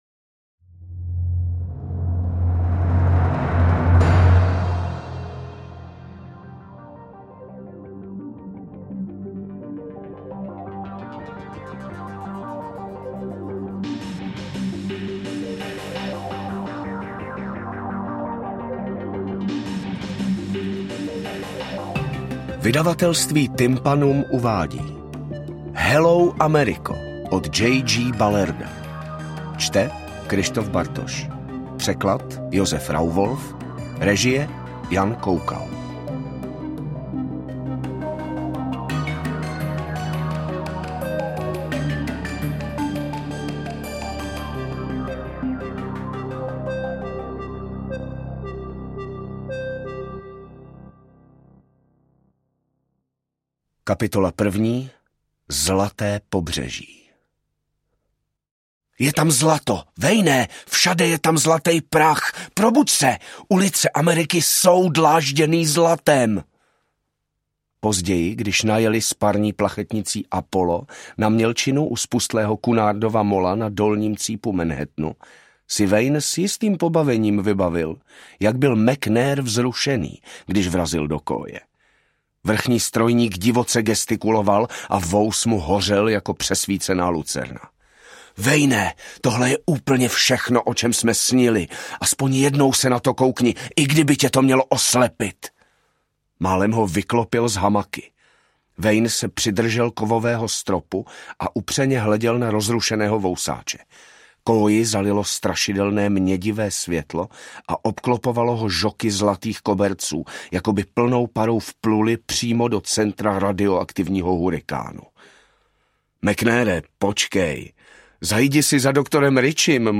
AudioKniha ke stažení, 33 x mp3, délka 8 hod. 16 min., velikost 460,6 MB, česky